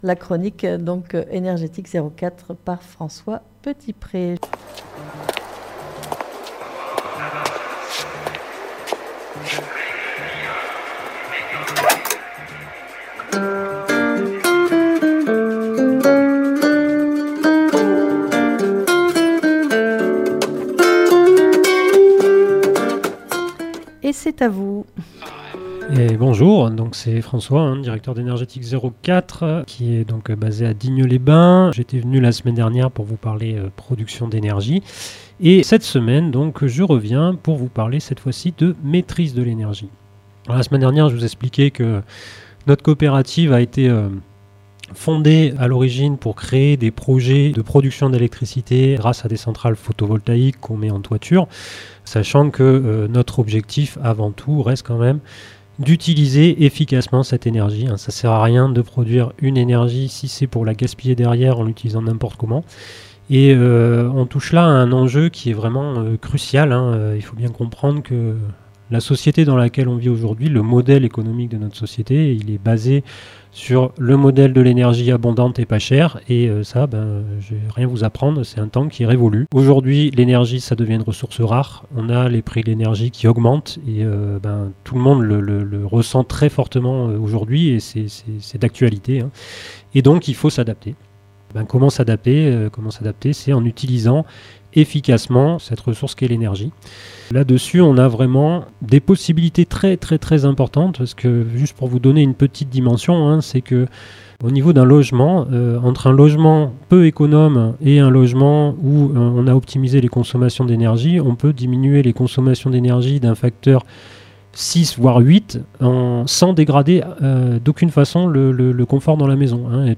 Lundi 14 Octobre 2024 Cette 2ème chronique ce lundi 14 octobre 2024 dans le magazine région Sud Est " A la bonne heure" sur Fréquence Mistral en direct de l'antenne de Digne les Bains porte sur le thème "La Maîtrise de l'énergie".